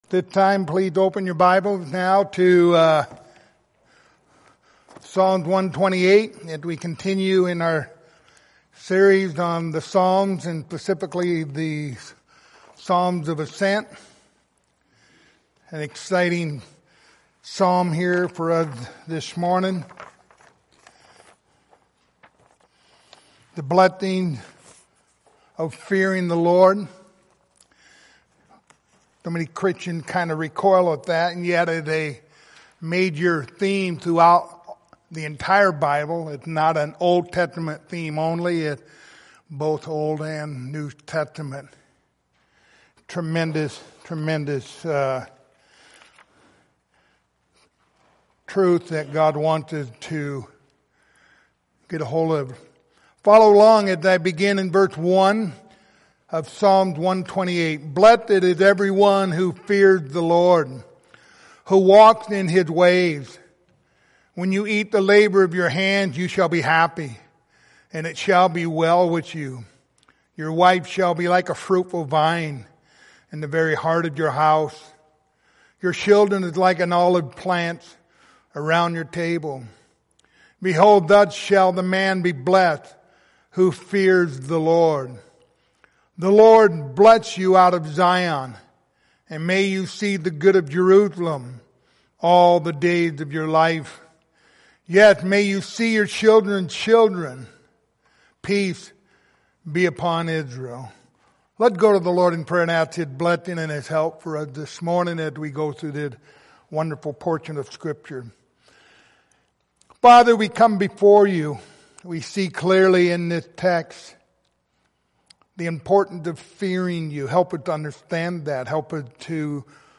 Passage: Psalm 128:1-6 Service Type: Sunday Morning